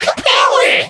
mech_mike_kill_vo_01.ogg